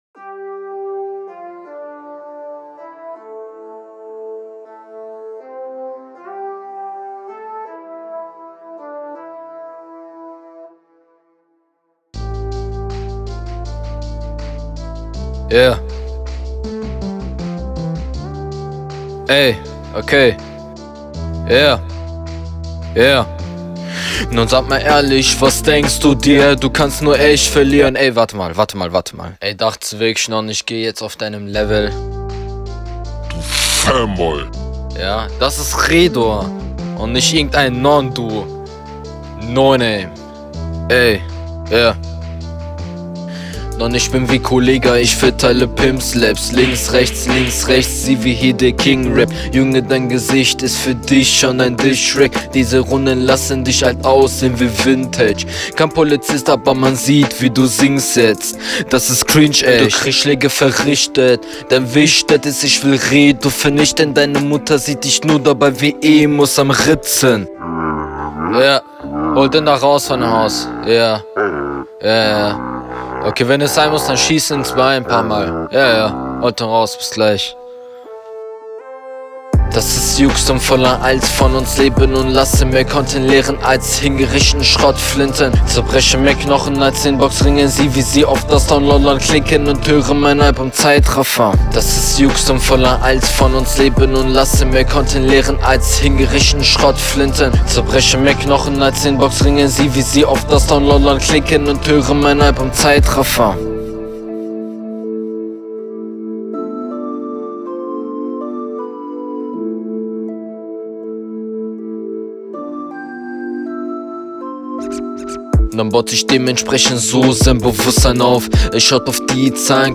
Viele hatten die Kollegahphase, aber dir fehlt es an der richtigen Struktur für einen Kollegahtype. …